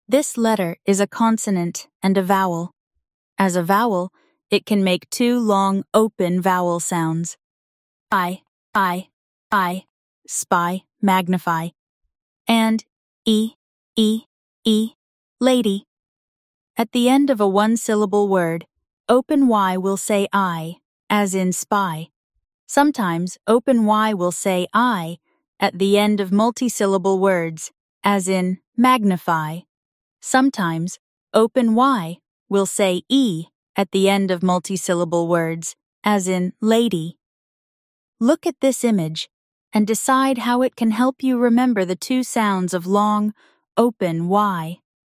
As a vowel, it can make two long open vowel sounds:
/ī/, /ī/, /ī/, spy, magnify and
/ē/, /ē/, /ē/, lady
At the end of a one-syllable word, Open Y will say /ī/, as in “spy”.